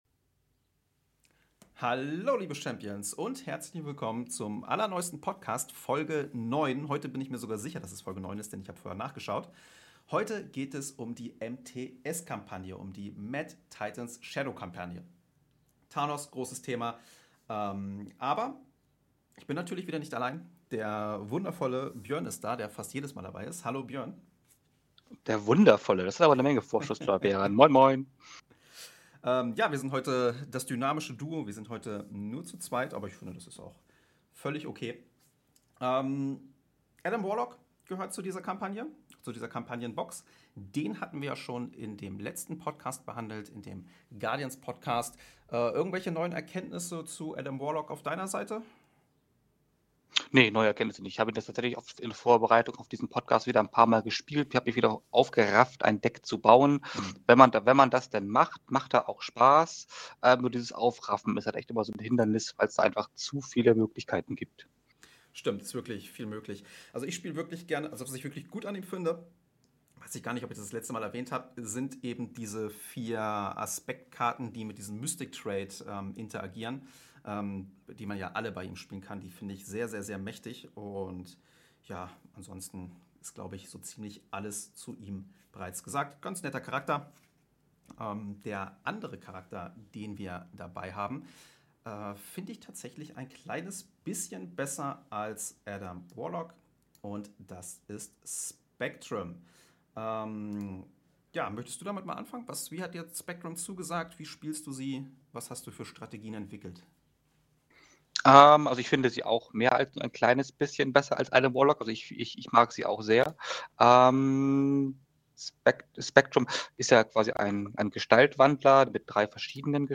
Daher lade ich jedes mal bis zu drei meiner Zuschauer ein um mich über verschiedene Themen zu unterhalten.